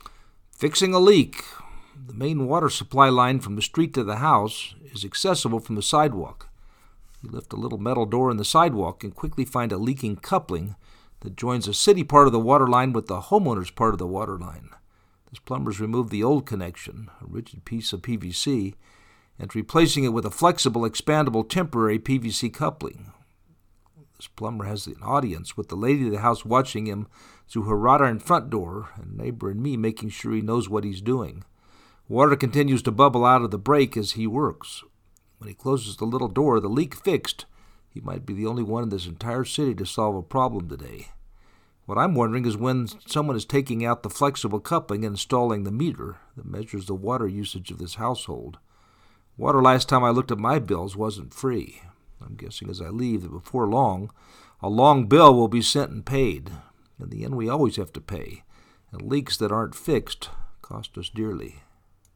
Water continues to bubble out of the break as he works.
fixing-a-leak-1.mp3